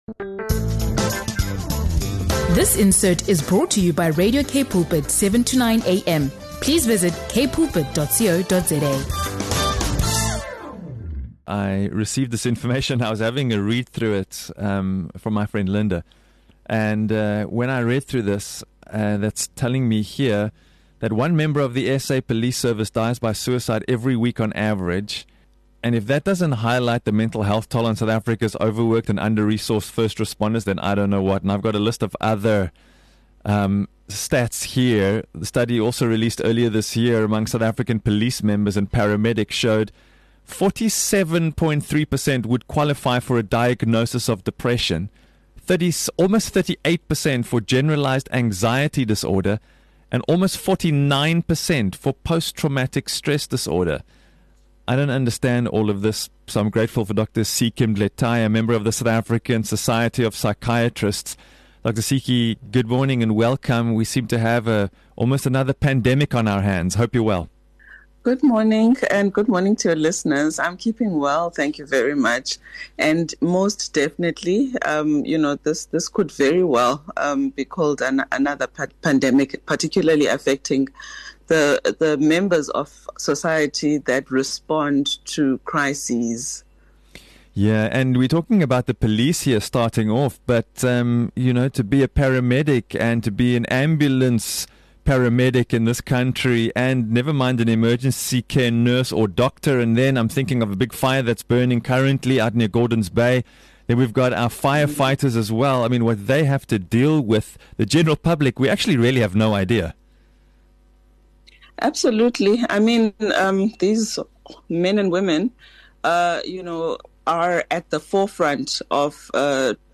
A mental health crisis is gripping South Africa's first responders, with staggering statistics revealing that, on average, one police officer dies by suicide every week. A recent study shows that 47.3% of police and paramedics qualify for a depression diagnosis, while nearly 49% show signs of PTSD. This discussion